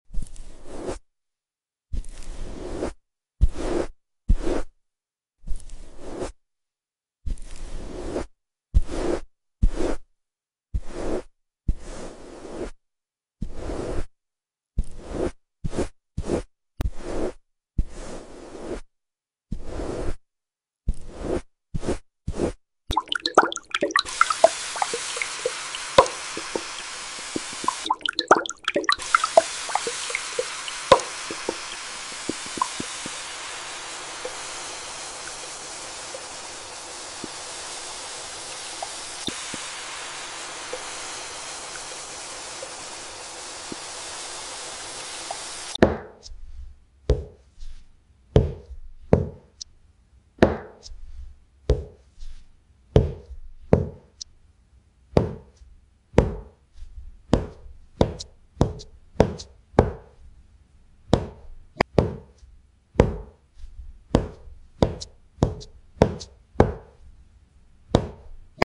Soft brushes, flowing water, and gentle tapping — the ultimate ASMR trigger mix for deep tingles and total calm.